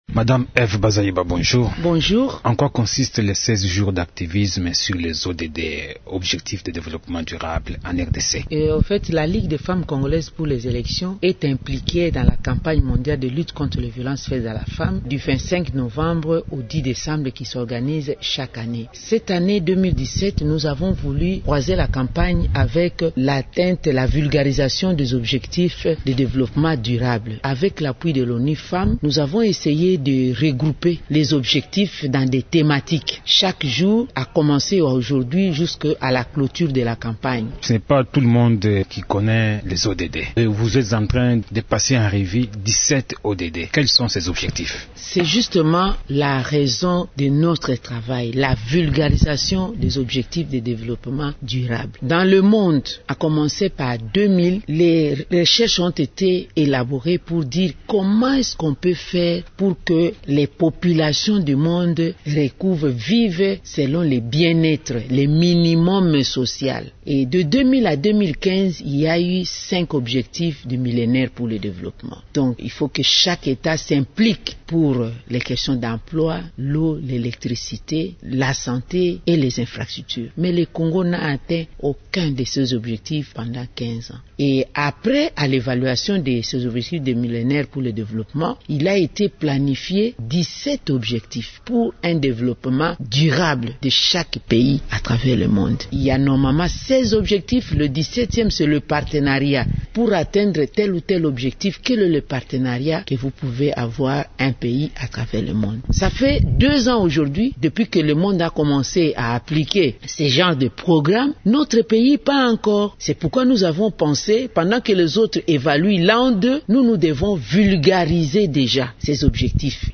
La présidente de cette organisation, la sénatrice Eve Bazaiba, invitée de Radio Okapi ce lundi, souligne que pour cette année, la LIFCE va mettre l’accent dans le cadre de cette campagne sur la vulgarisation des objectifs du développement durable.